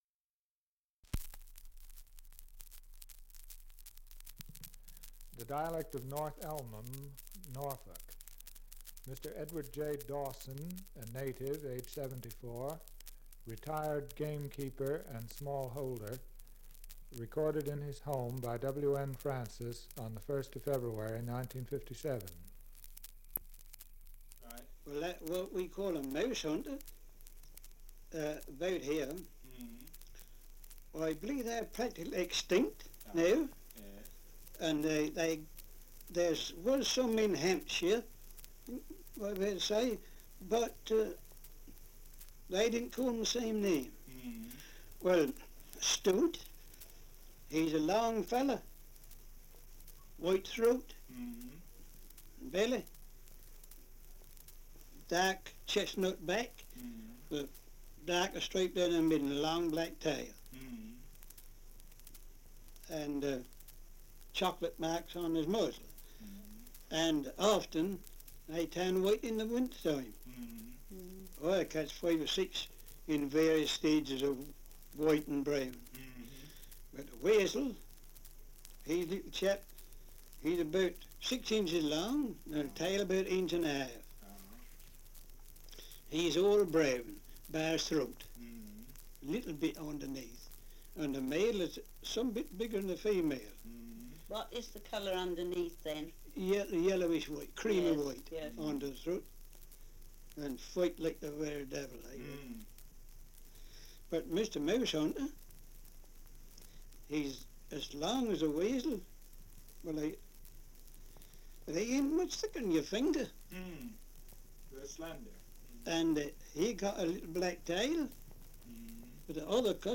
Survey of English Dialects recording in North Elmham, Norfolk
78 r.p.m., cellulose nitrate on aluminium